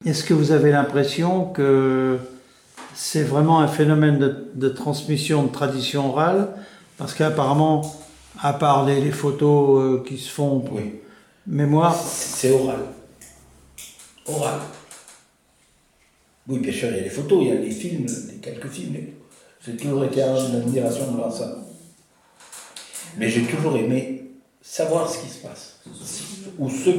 Enquête Enquête ethnologique sur les fêtes des bouviers et des laboureurs avec l'aide de Témonia
Catégorie Témoignage